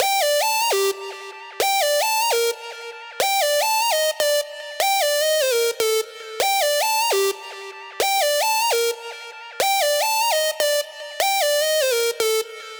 150BPM Lead 02 Gmin.wav